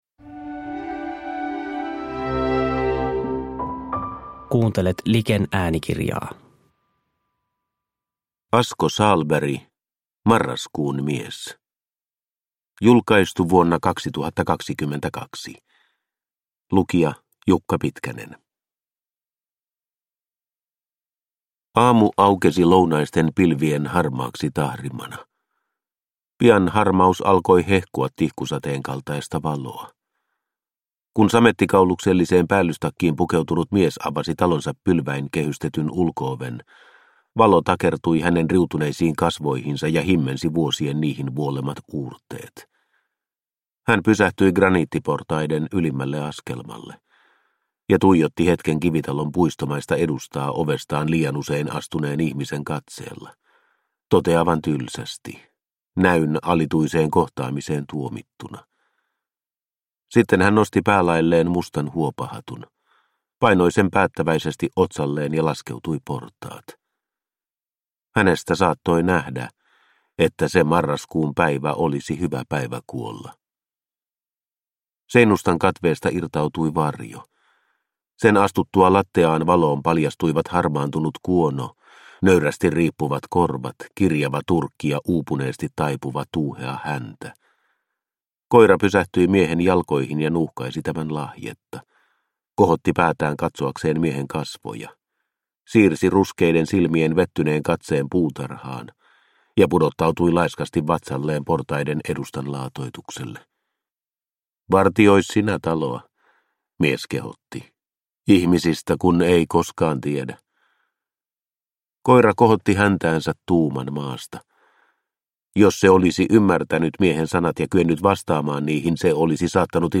Marraskuun mies – Ljudbok – Laddas ner